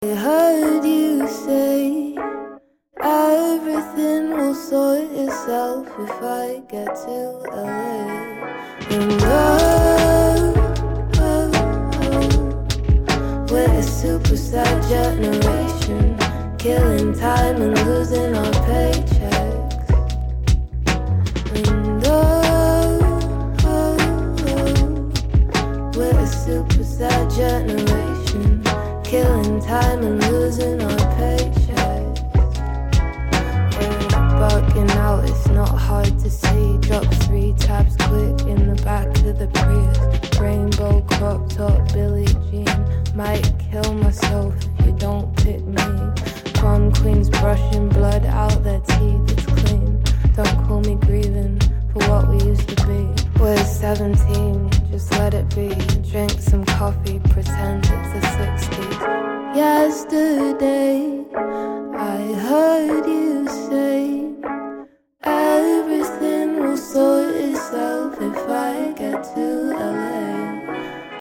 Sweet laid back back beats with quality lyrical content.